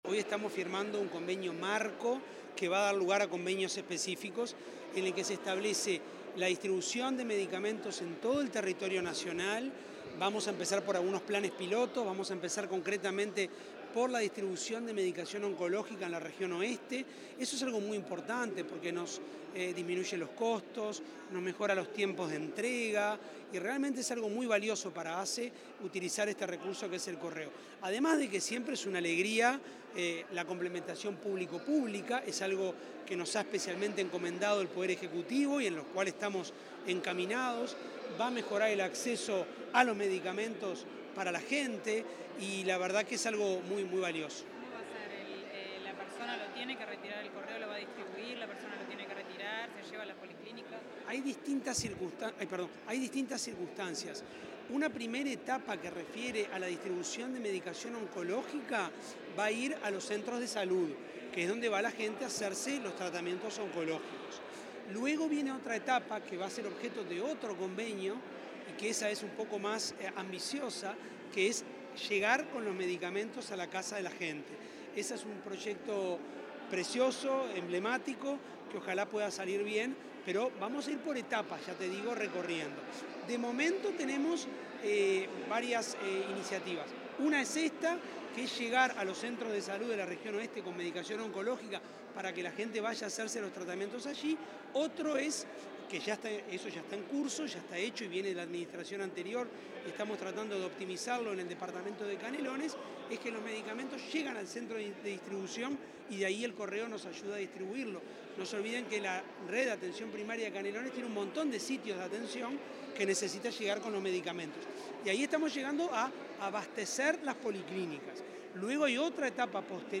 El presidente de ASSE, Álvaro Danza, brindó declaraciones a la prensa, tras la firma de un convenio con el Correo Uruguayo.